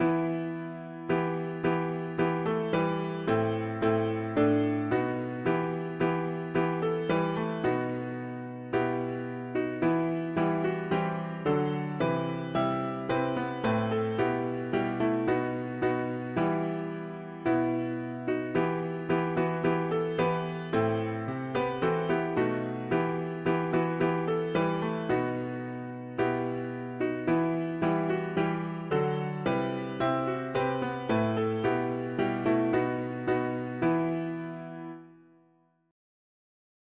I’m bound for the promised land, … english theist 4part chords
American traditional
Key: E minor Meter: CM with refrain
on-jordans-stormy-banks-minor.mp3